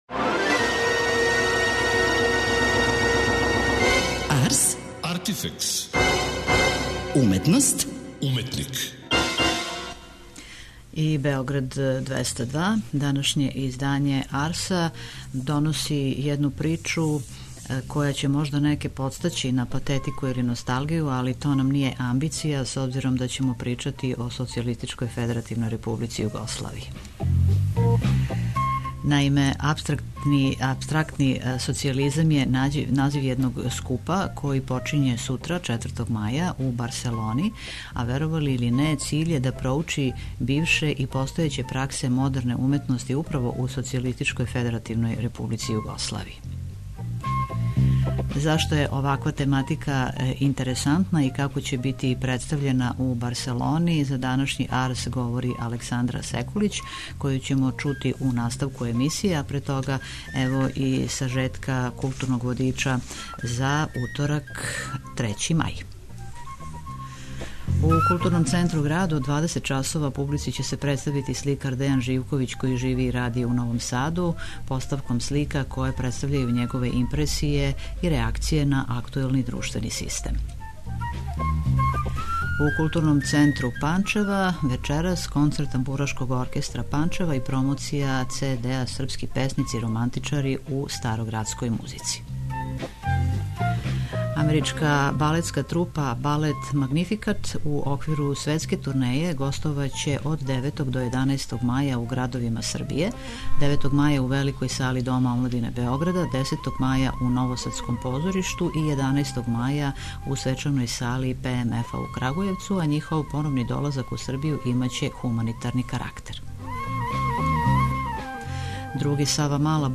преузми : 27.12 MB Ars, Artifex Autor: Београд 202 Ars, artifex најављује, прати, коментарише ars/уметност и artifex/уметника.